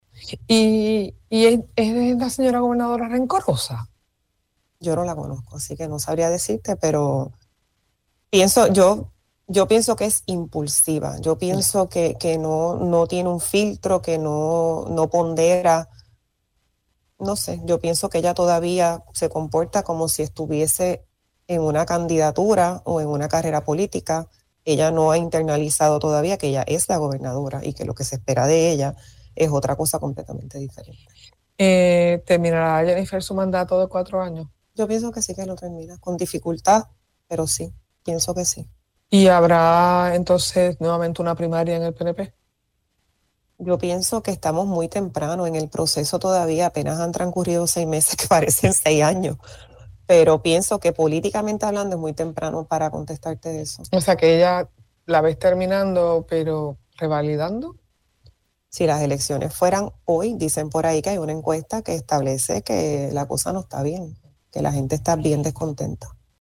La exdirectora ejecutiva de la Autoridad del Distrito de Convenciones, Mariela Vallines opinó en El Calentón que la gobernadora Jenniffer González se comporta cómo si siguiera en la campaña política y no como la primera ejecutiva.